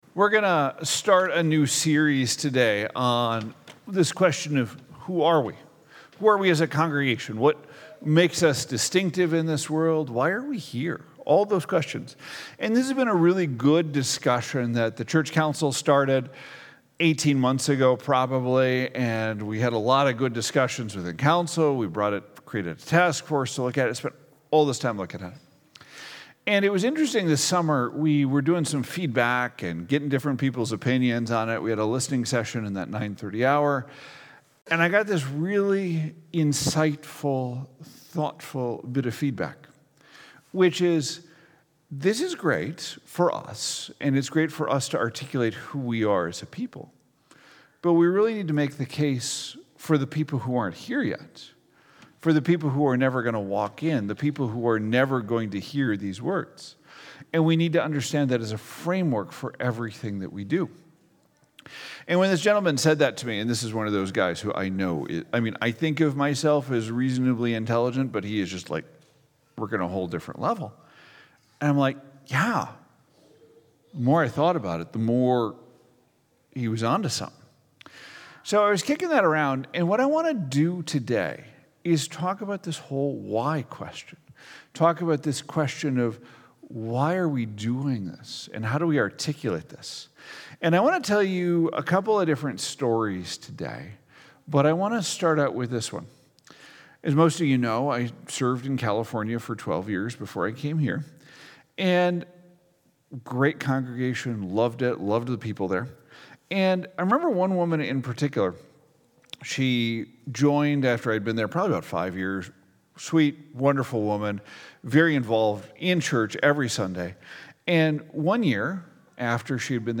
2025 Who Are We Grow Love Serve Sunday Morning Sermon Series